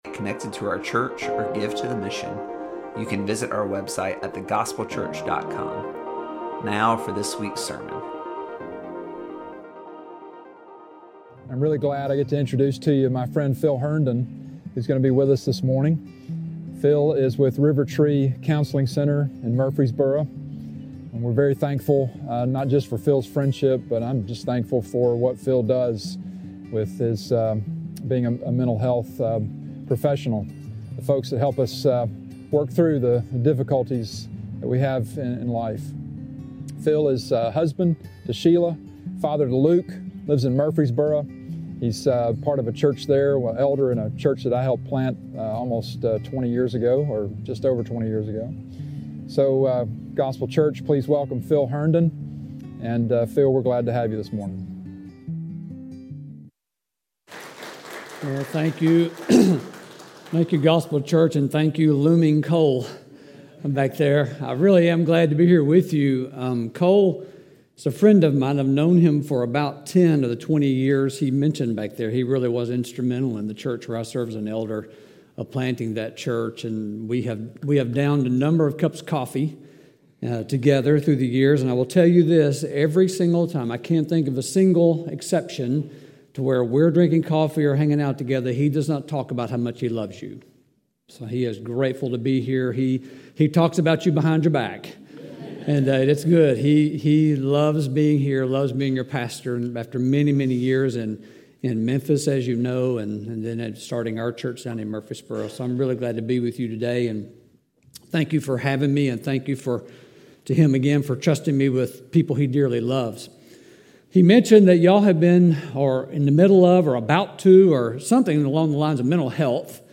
preaches for us this morning on the core of emotional health.